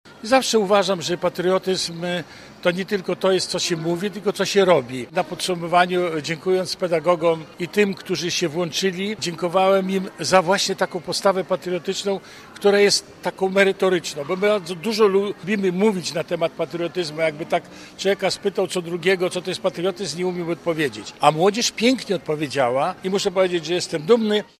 – Najważniejszym celem było zainteresowanie młodych ludzi znaczeniem naszych symboli narodowych – mówi senator PO Władysław Komarnicki: